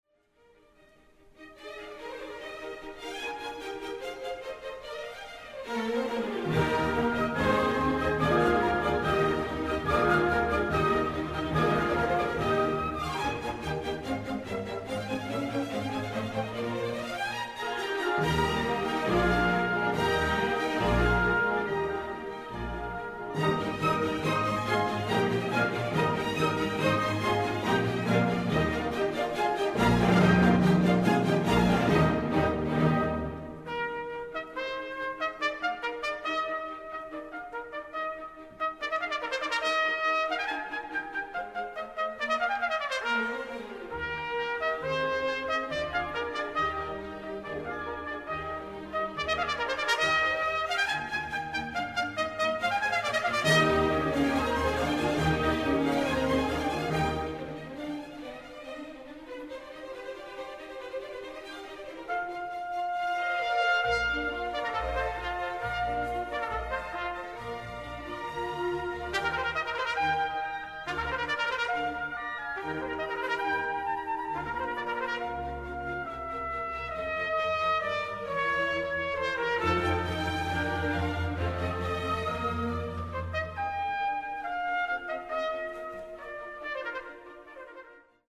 El Concierto para Trompeta y Orquesta en mi bemol mayor (Concerto per il Clarino) es el único que creó Haydn para este instrumento en 1796, cuando contaba con 64 años de edad, mismo que se estrenó el 22 de marzo de 1800 en Viena, en el antiguo Burgtheater, actualmente ya demolido.